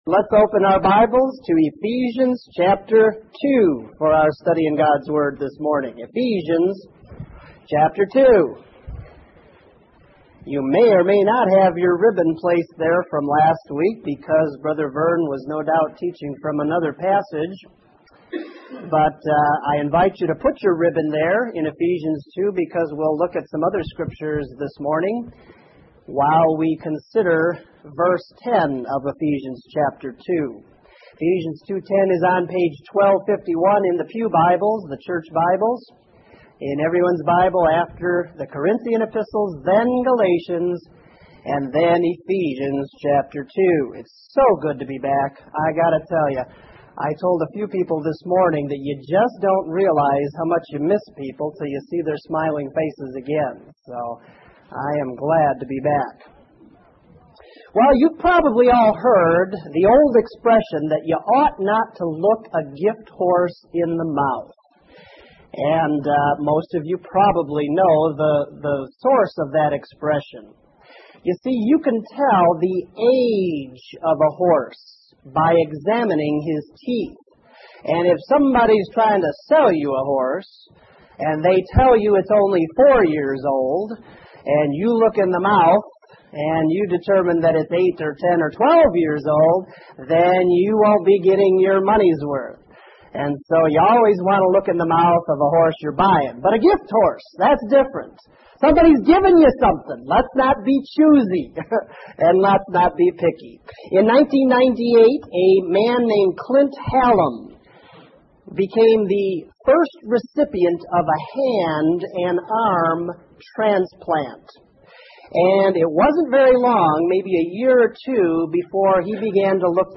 When you're done, explore more sermons from this series.